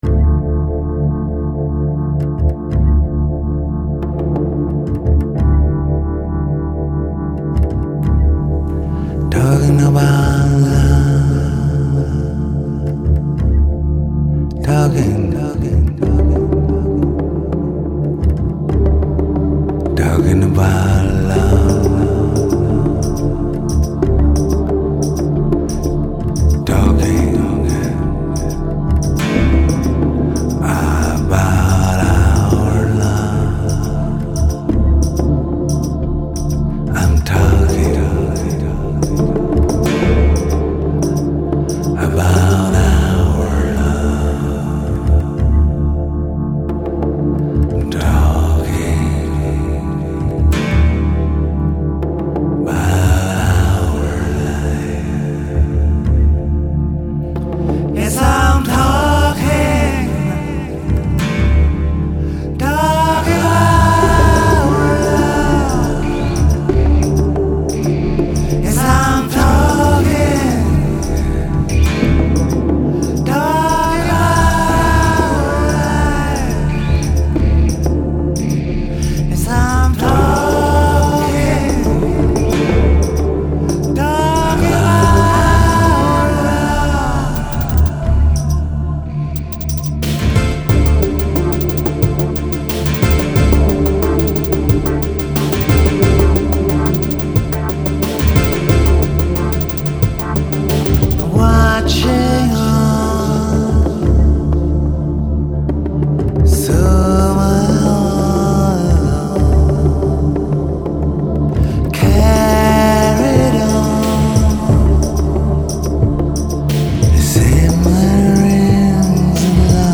Instr & Voc